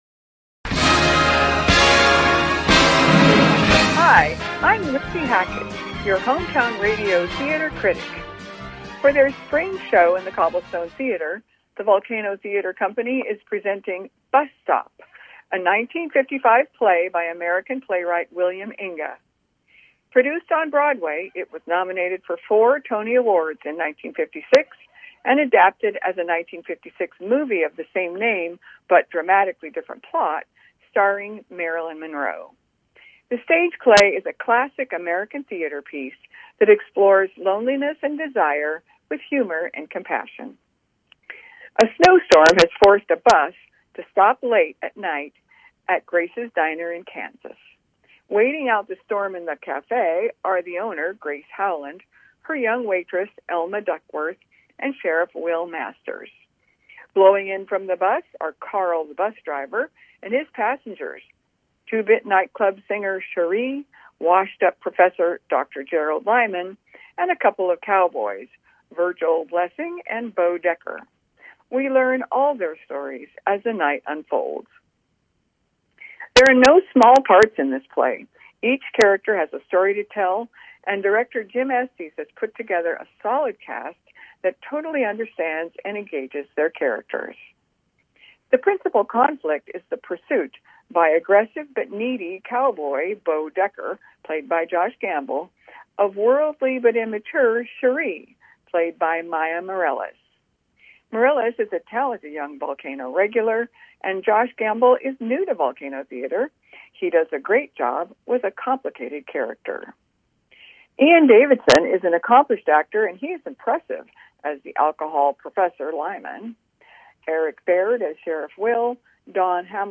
KVGC Review of Bus Stop
KVGC-Bus-Stop-Review.mp3